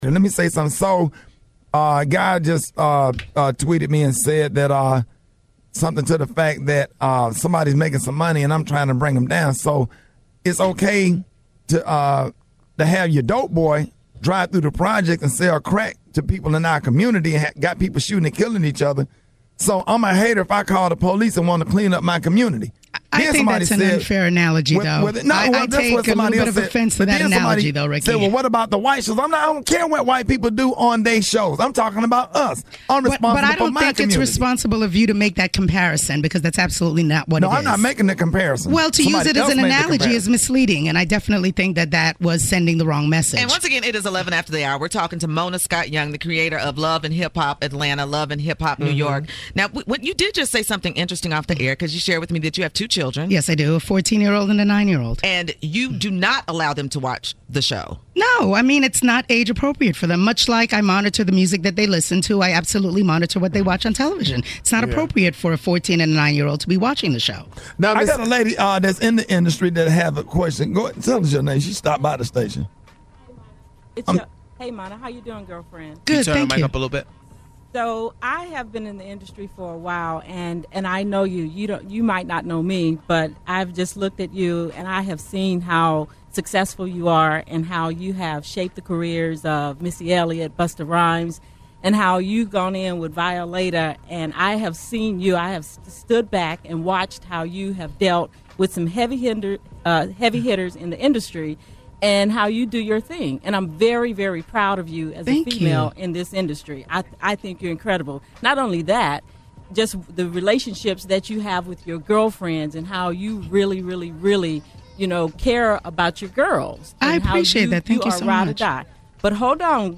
Creator and Master Mind Behind: Love and Hip Hop: Mona Scott Young Visits The Rickey Smiley Morning Show